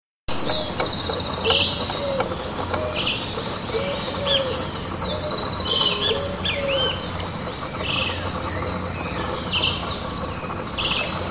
Chivi Vireo (Vireo chivi)
Spanish Name: Chiví Común
Life Stage: Adult
Location or protected area: Reserva Natural del Pilar
Condition: Wild
Certainty: Recorded vocal